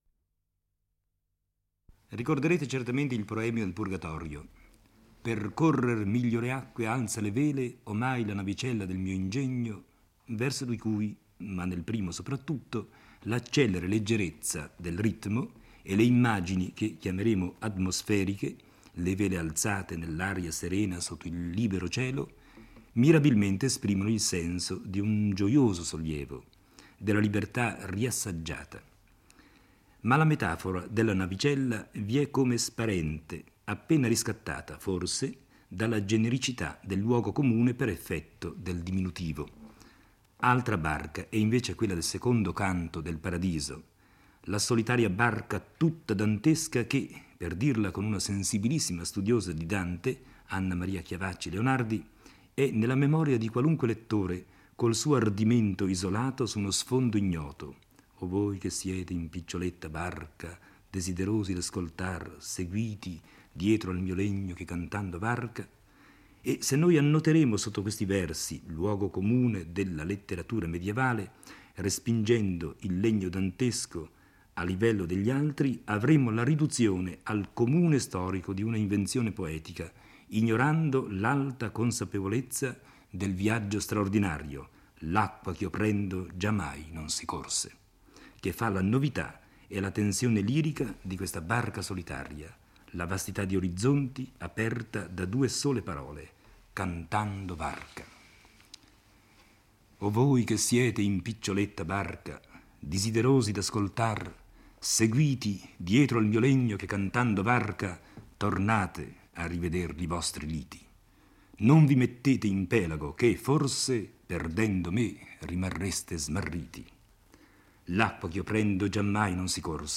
legge e commenta il II canto del Paradiso. Dante e la guida si muovono velocissimi e, mentre Beatrice fissa il sole, Dante a sua volta la contempla, sorprendendosi poi per il proprio arrivo nel primo cielo. La sua impressione è quella di essere avvolto in una nube, e il cielo della Luna è paragonato all'acqua che riceve un raggio di luce senza disunirsi.